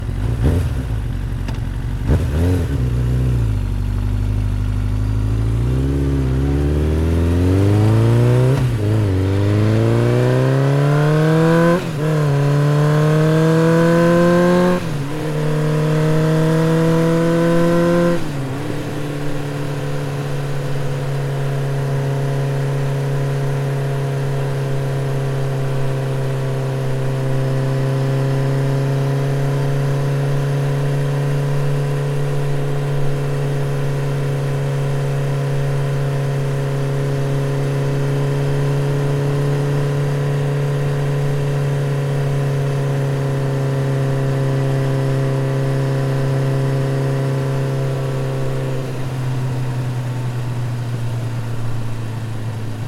Accelerator sound:-
car-Accelerator.mp3